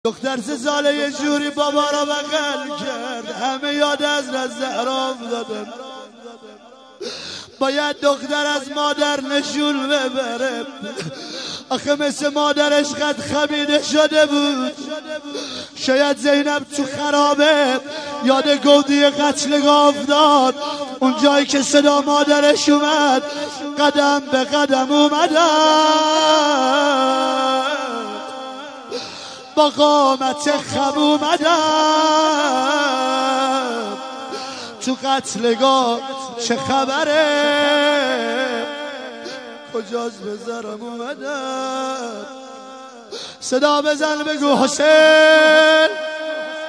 شب سوم_محرم 94_روضه حضرت رقیه(س)
روضه